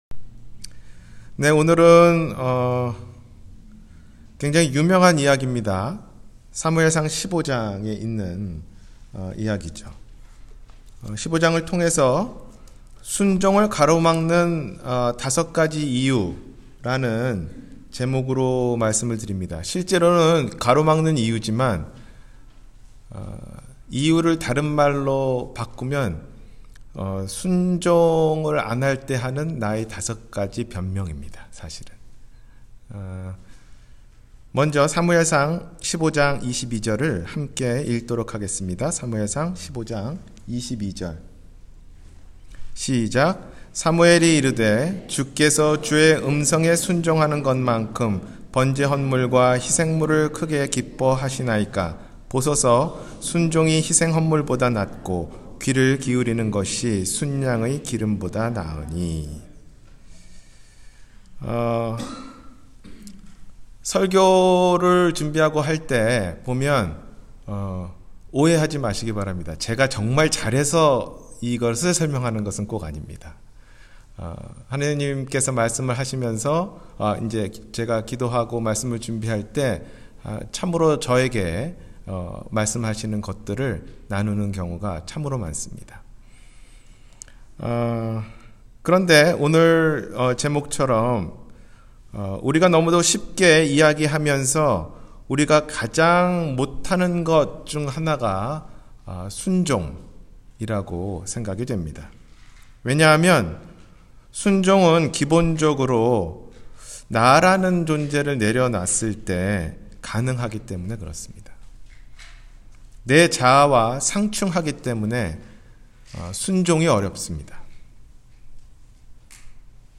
순종을 막는 다섯가지 이유-주일설교